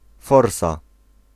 Ääntäminen
UK : IPA : /dəʊ/ US : IPA : /doʊ/